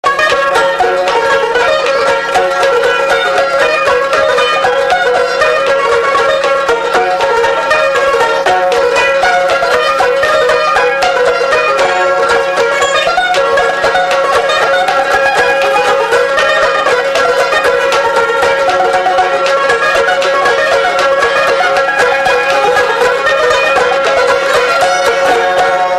Valse
Instrumental
Pièce musicale inédite